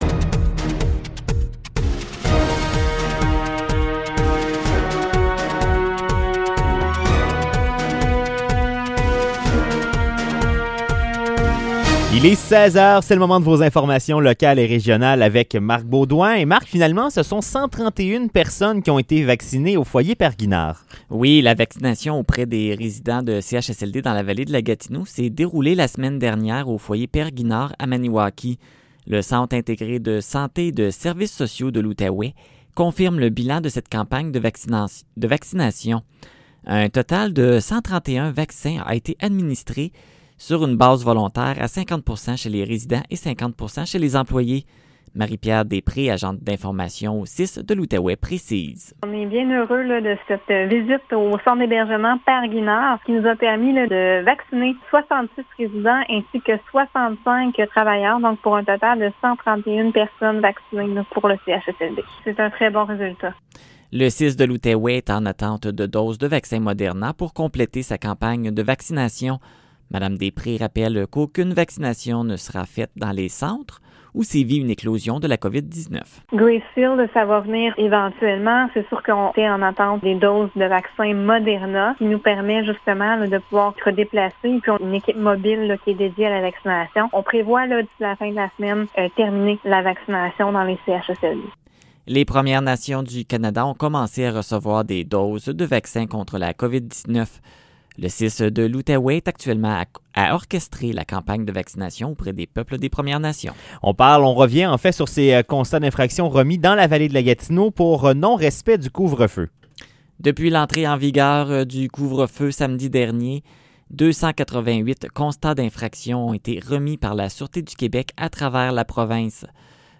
Nouvelles locales - 12 janvier 2021 - 16 h